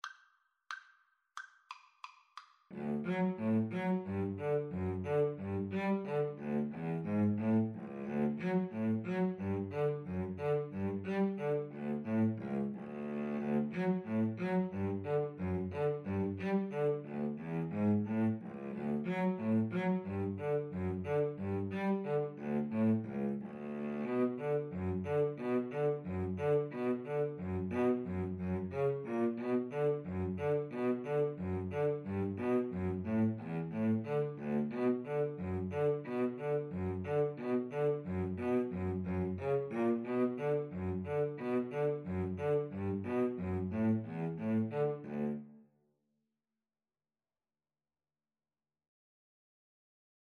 Free Sheet music for Violin-Cello Duet
D major (Sounding Pitch) (View more D major Music for Violin-Cello Duet )
=90 Fast two in a bar
Traditional (View more Traditional Violin-Cello Duet Music)